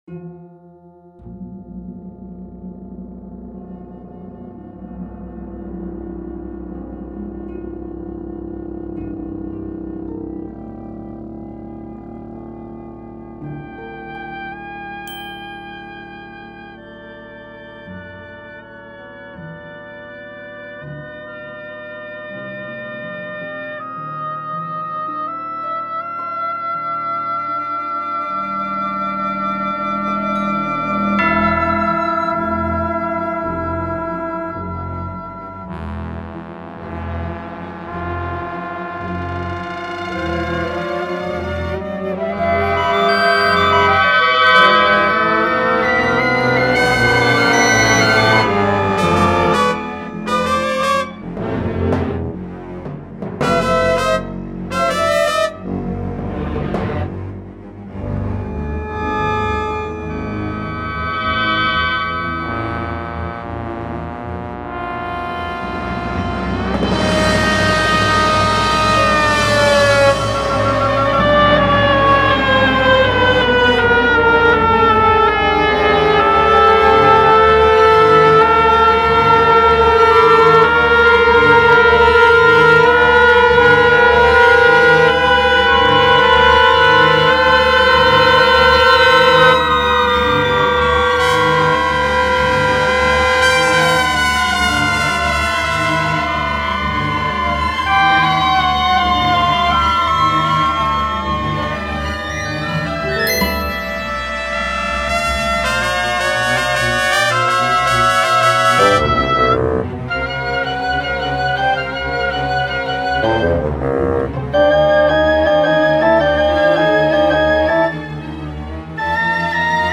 Versión en Sampler.
Mientras les dejo el primer movimiento en versión virtual.